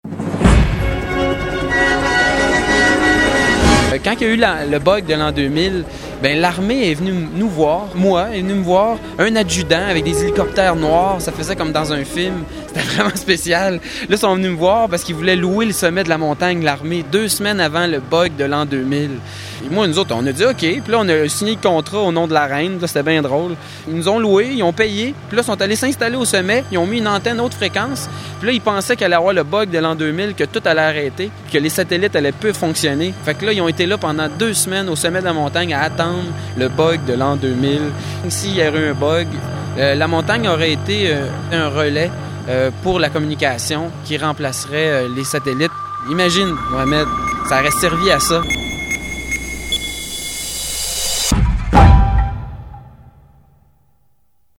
reportage 9.59'